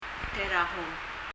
» pink eye 結膜炎 terahom [tɛrəhɔm] 英） pink eye 日） 結膜炎 Leave a Reply 返信をキャンセルする。